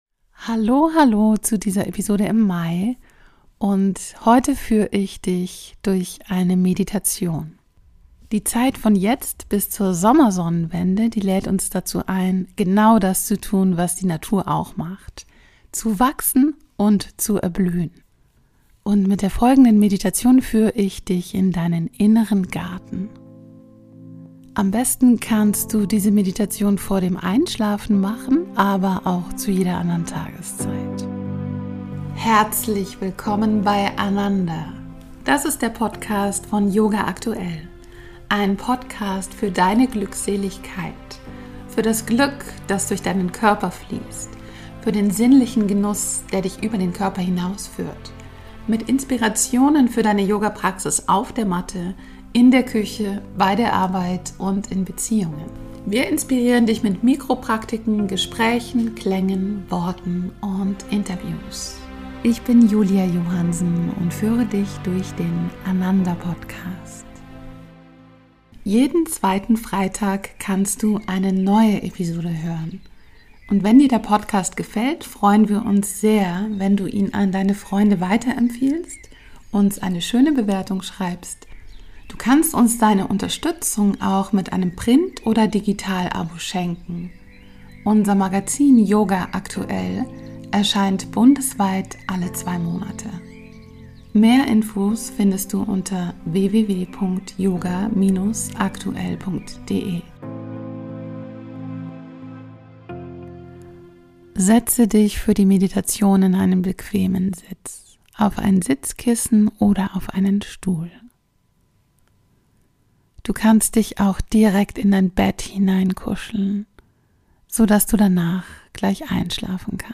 Eine Meditation für den Frühling - verbinde dich mit deinem inneren Garten